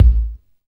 Index of /90_sSampleCDs/Roland L-CD701/KIK_Natural Kick/KIK_Natural K2
KIK BODY K1.wav